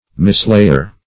Mislayer \Mis*lay"er\, n. One who mislays.
mislayer.mp3